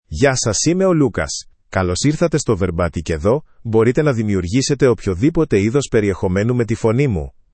LucasMale Greek AI voice
Lucas is a male AI voice for Greek (Greece).
Voice sample
Male
Lucas delivers clear pronunciation with authentic Greece Greek intonation, making your content sound professionally produced.